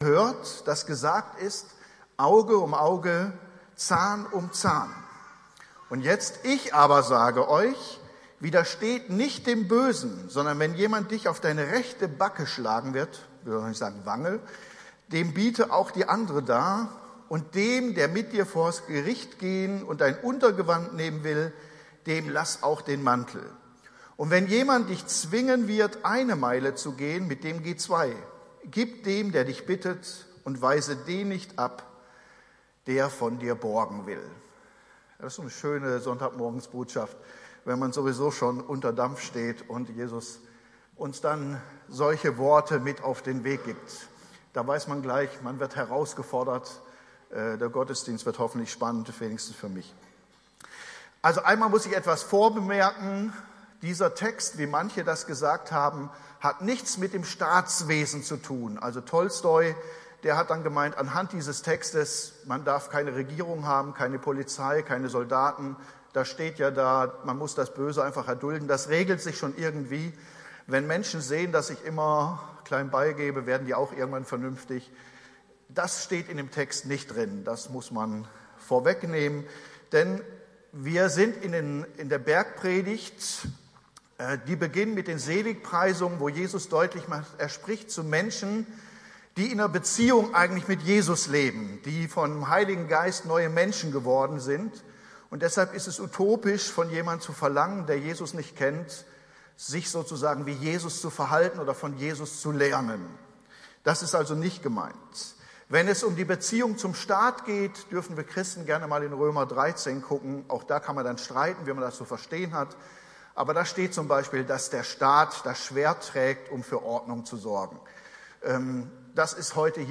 Predigtreihe: Die Bergpredigt